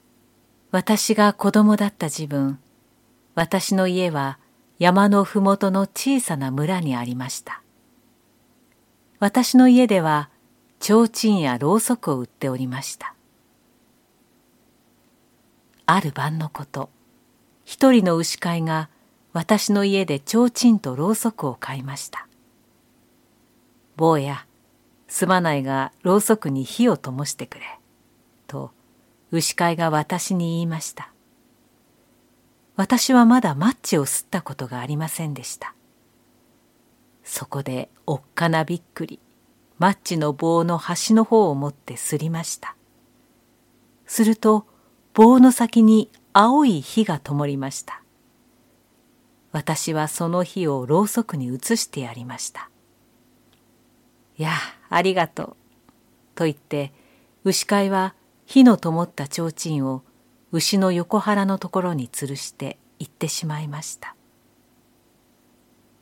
ボイスサンプル
朗読2